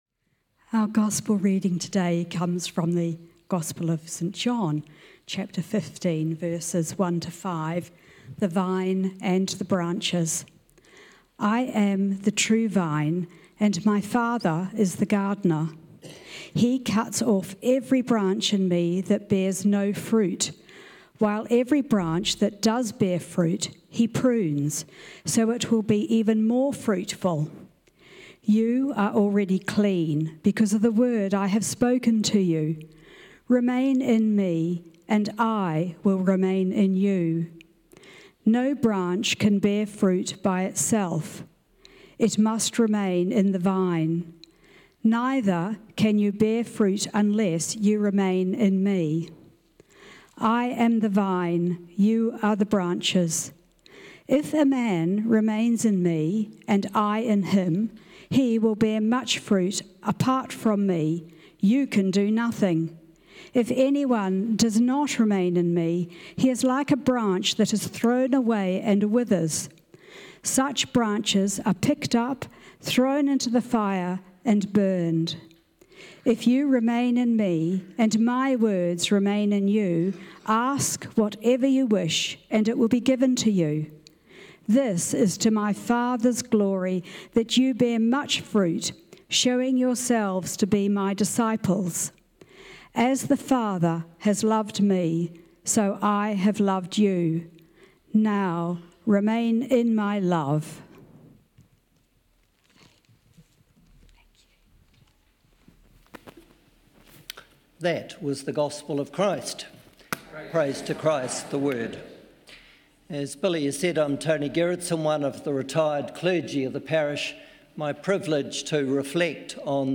Sermons | Whanganui Anglicans